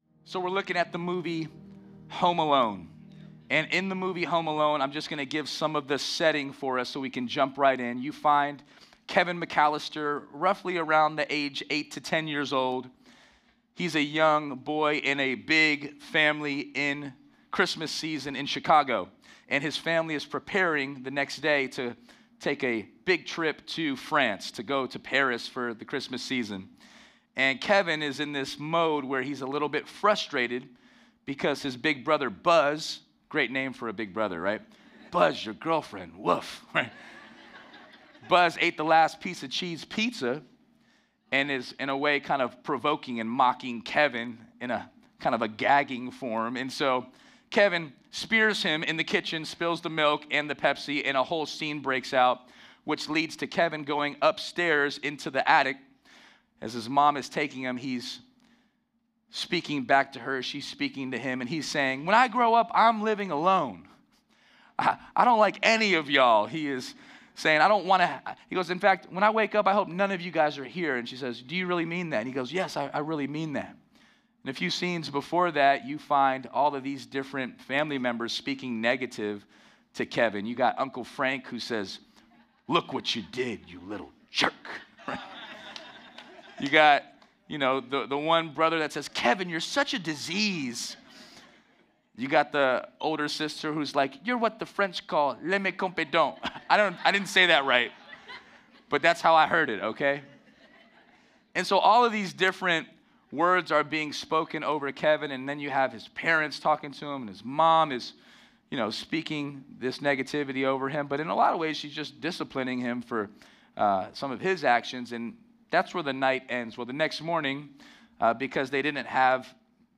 Sermons podcast of Walk Church in Las Vegas, NV